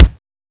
soft-hitnormal.wav